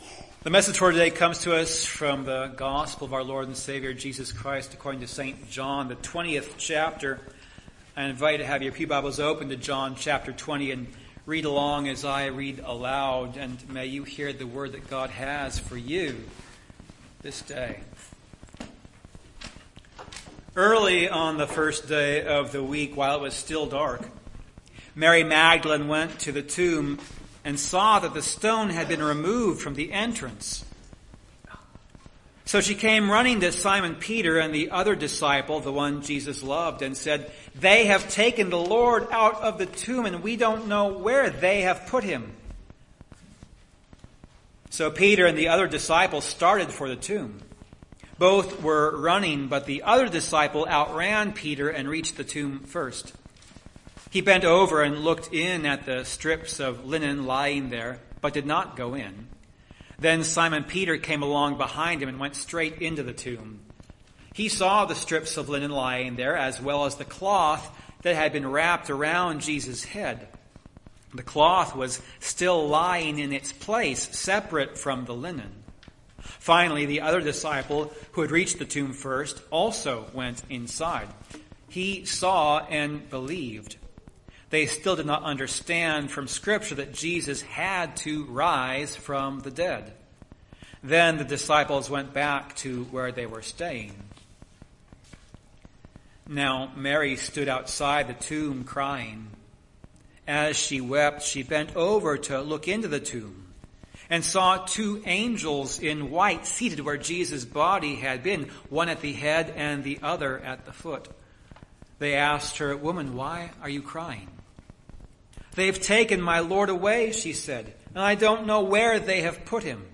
Service Type: Resurrection of the Lord (Easter)